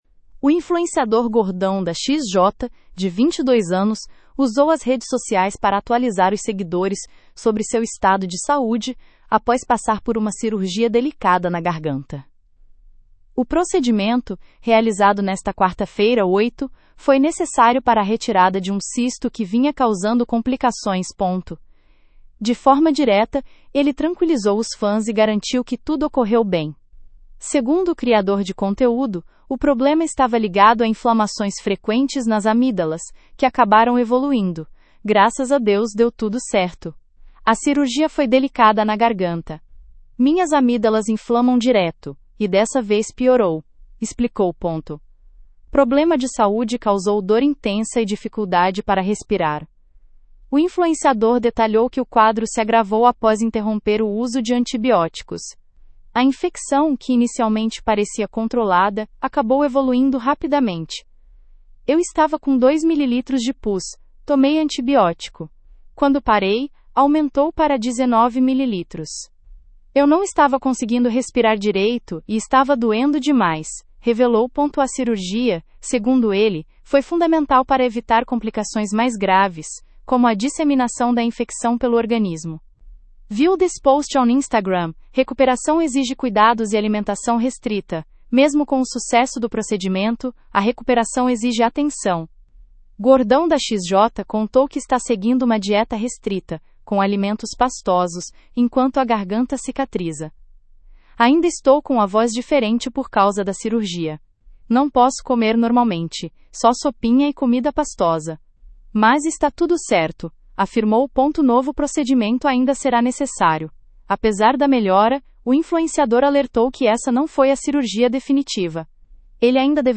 Segundo ele, a rouquidão é temporária e deve desaparecer em até 48 horas.